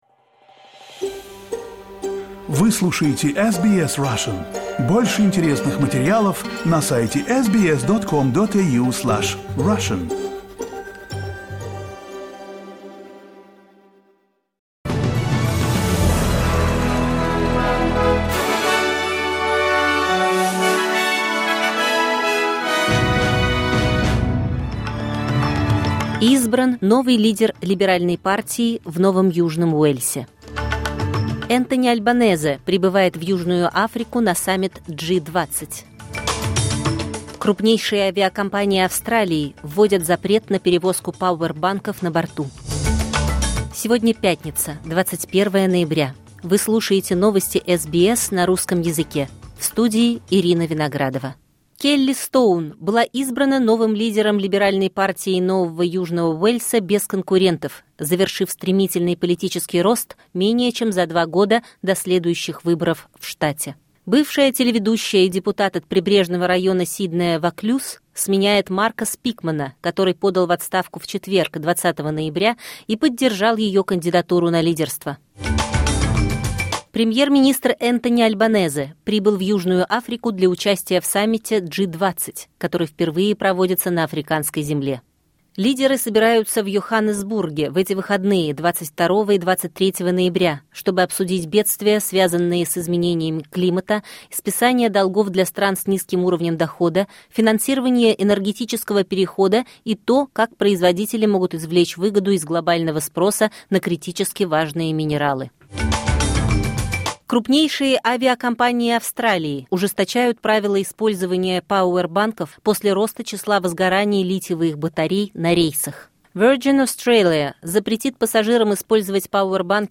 Новости SBS на русском языке — 21.11.2025